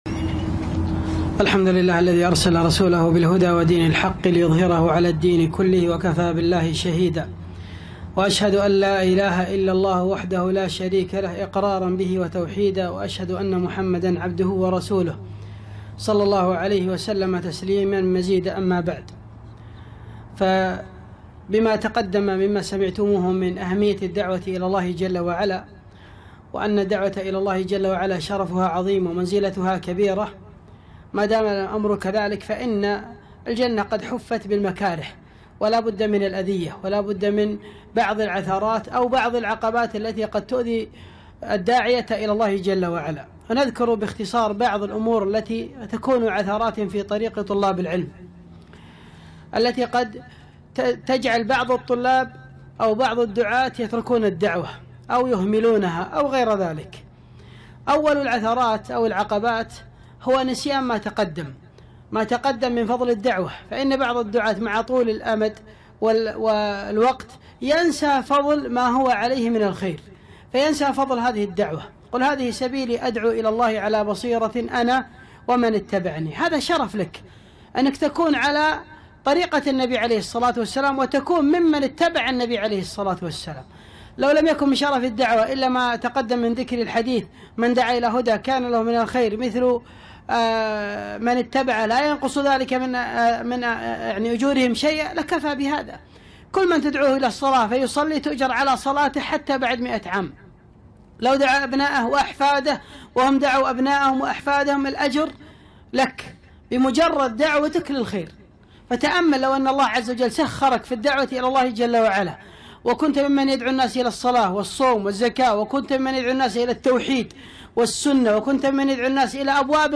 محاضرة - عثرات في طريق الدعاة